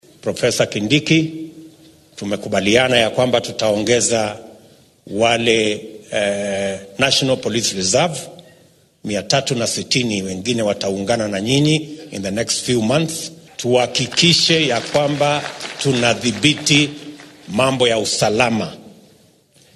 Madaxweynaha dalka William Ruto ayaa ka hadlay arrimaha ammaanka isagoo shaaciyay in bilooyinka nagu soo aaddan ismaamulka Laikipia laga howlgelin doono 360 booliiska qaran ee keydka ee NPR oo dheeraad ah.